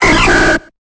Cri de Qwilfish dans Pokémon Épée et Bouclier.